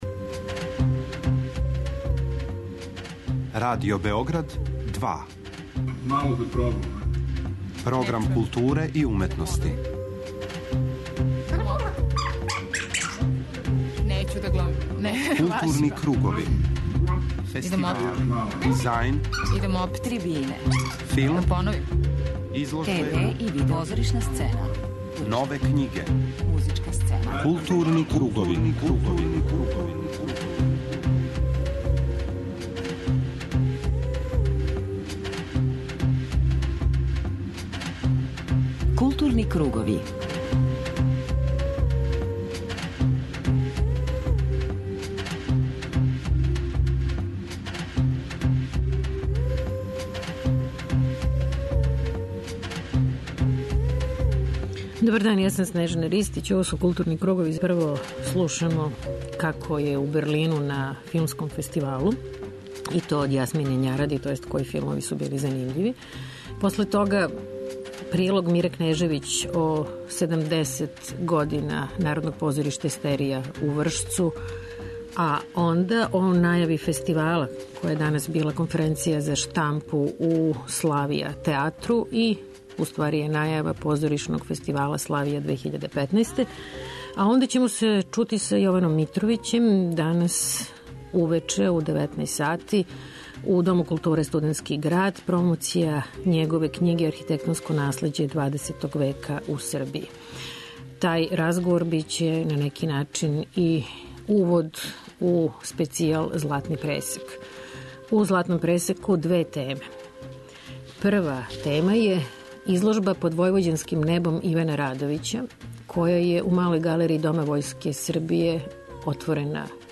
У данашњем Златном пресеку чућете разговор са кустосима и аутором.